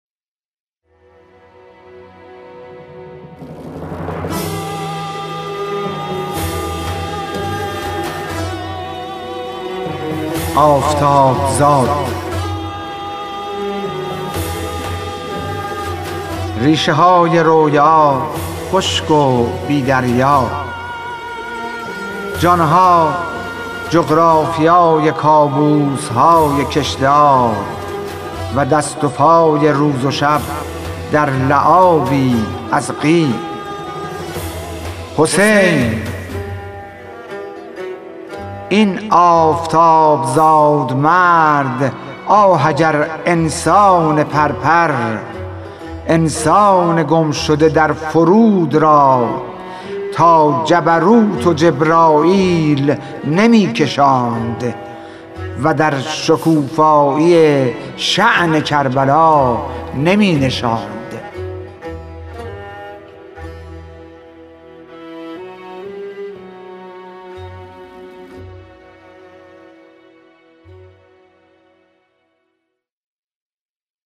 خوانش شعر سپید عاشورایی/ ۱